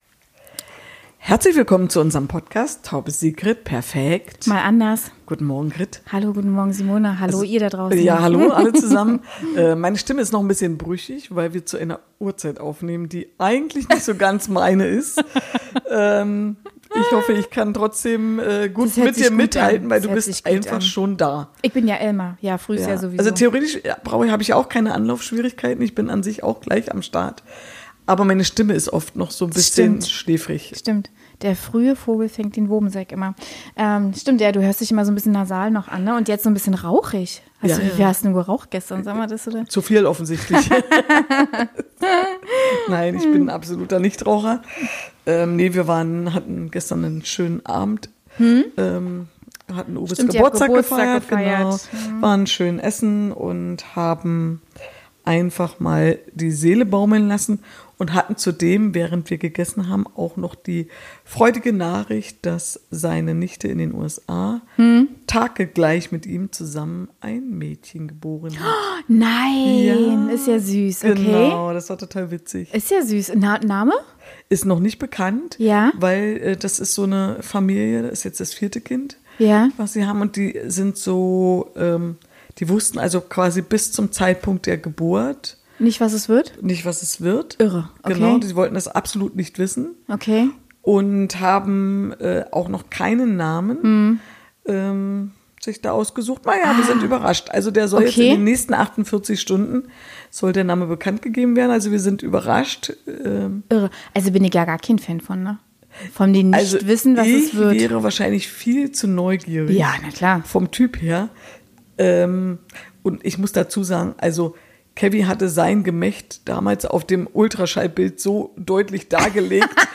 Wir diskutieren in geselliger Runde rund um das Thema Freundschaften. Was dürfen Freunde und was ist tatsächlich ein No-Go. Es kommt nicht auf die Quantität von Freunden an, sondern viel mehr auf die Qualität.